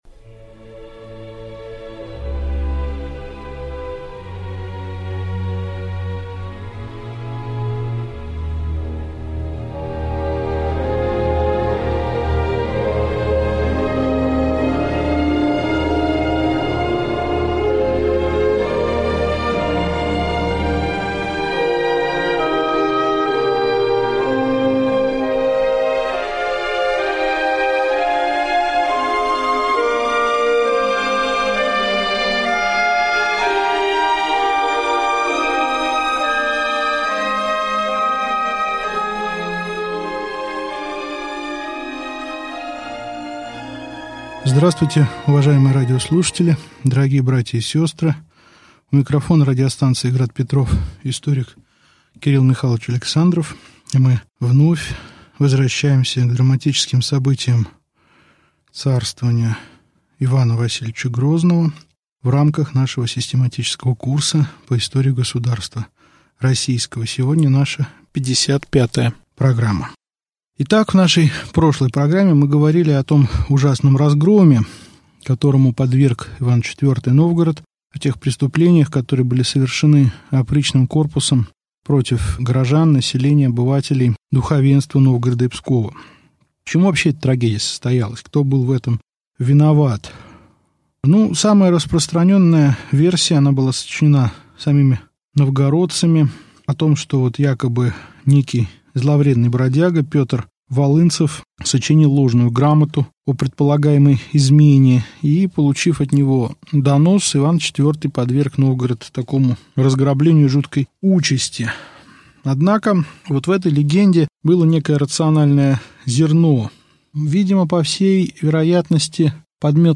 Аудиокнига Лекция 55. Период Большого террора 1567-1570 гг | Библиотека аудиокниг